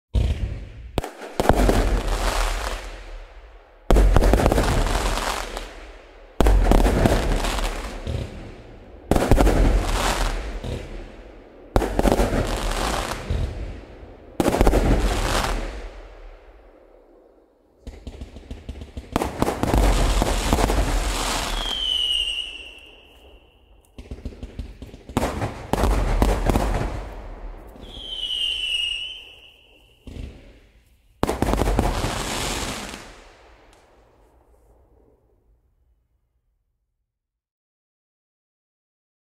دانلود آهنگ آتش بازی 2 از افکت صوتی انسان و موجودات زنده
جلوه های صوتی
دانلود صدای آتش بازی 2 از ساعد نیوز با لینک مستقیم و کیفیت بالا